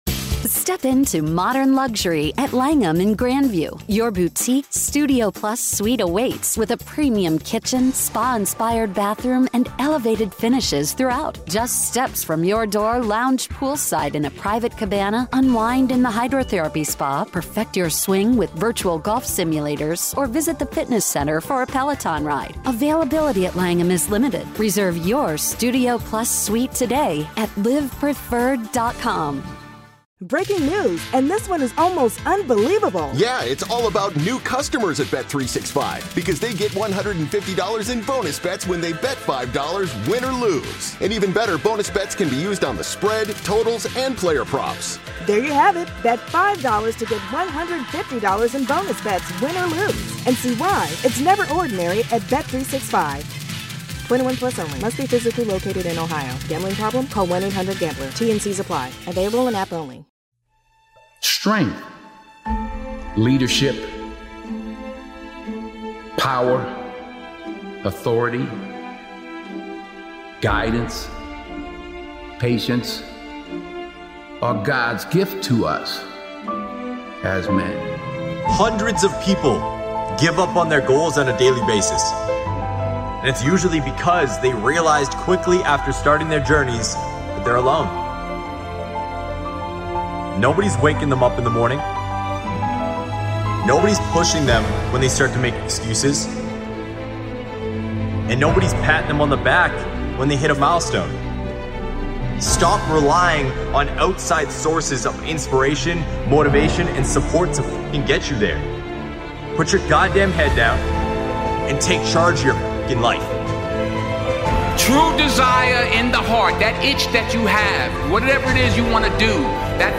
This event features powerful speeches